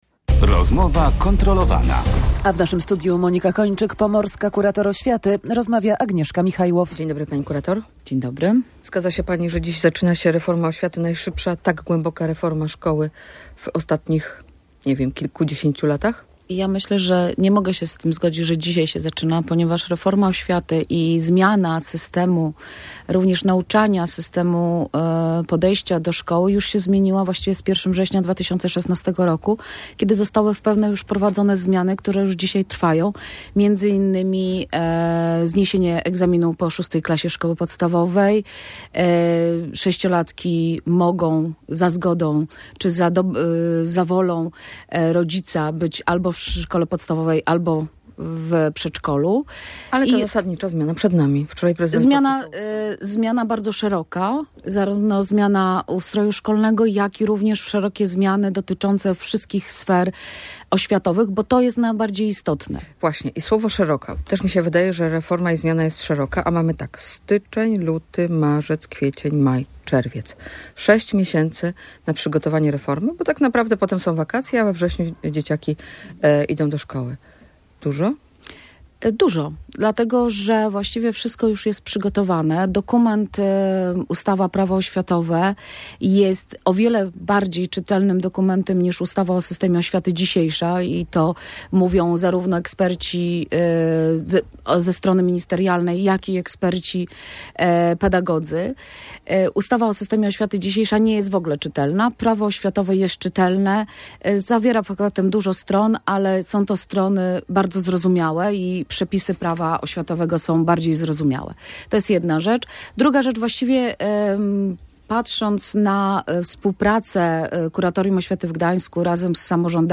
- To, jak samorządy podeszły do przygotowań do reformy edukacji, oceniam na pięć - mówi w Radiu Gdańsk pomorska kurator